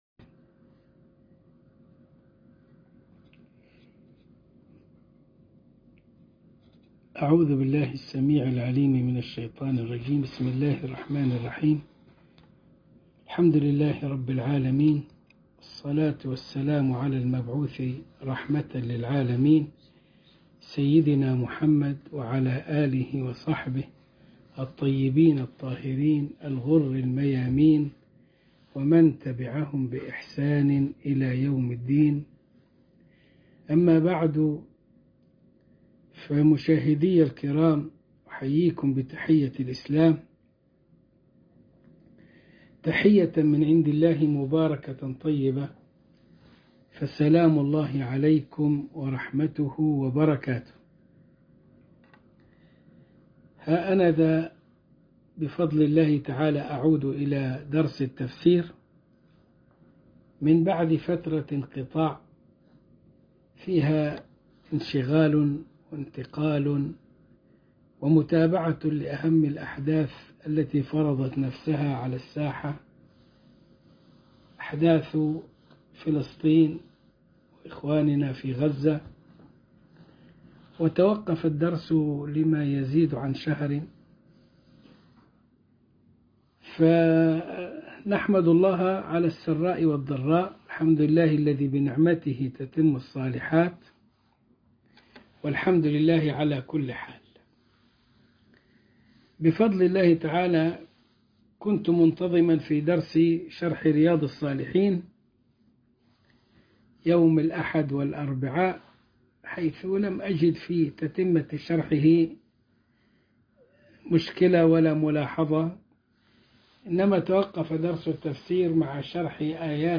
الدرس الأول من الإبريز في تفسير الكتاب العزيز سورة الملك من جزء تبارك